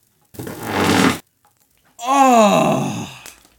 fart and relief sound
fart-and-relief-sound.mp3